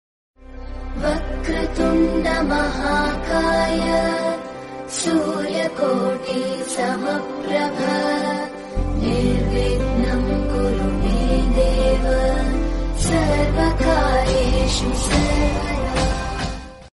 Bhakti Devotional